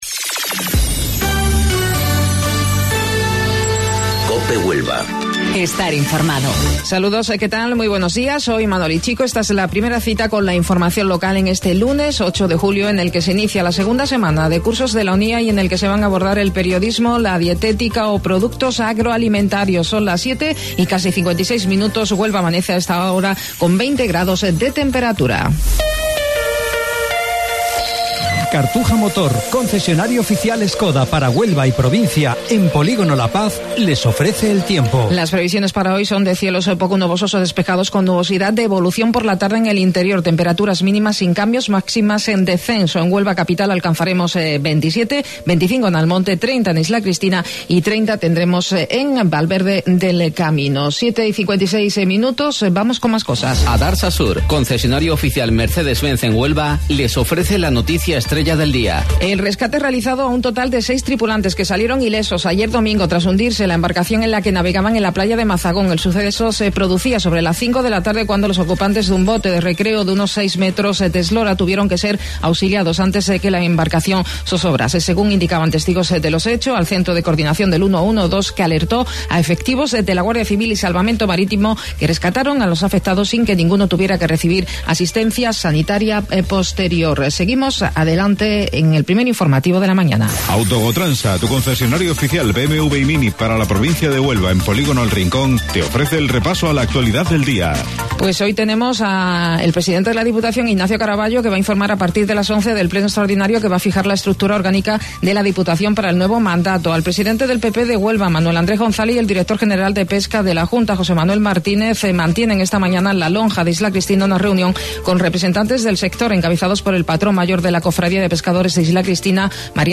AUDIO: Informativo Local 07:55 del 8 de Julio